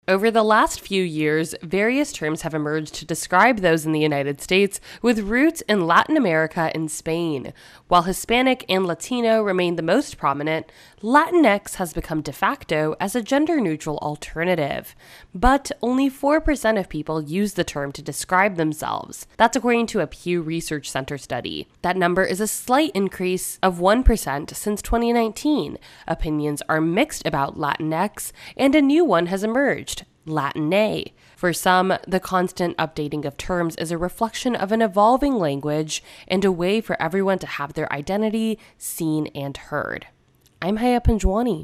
(Latinx is pronounced LAT'-ehn-ehks)